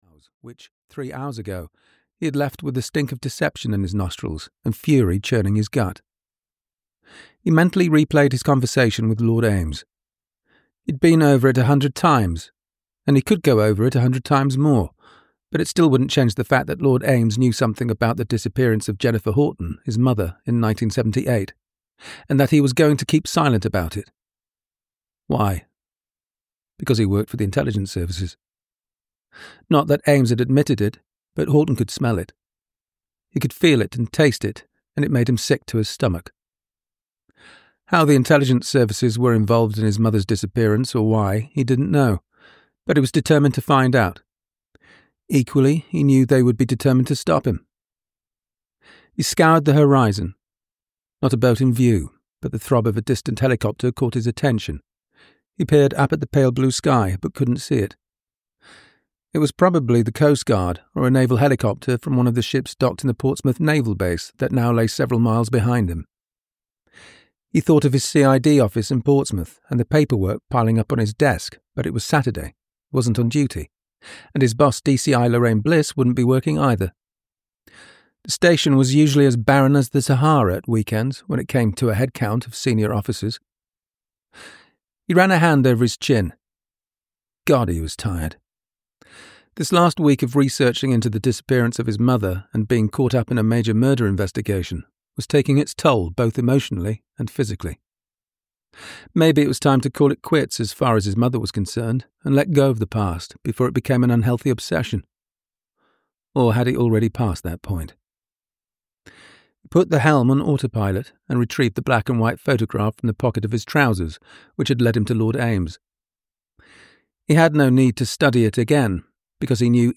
The Cowes Week Murders (EN) audiokniha
Ukázka z knihy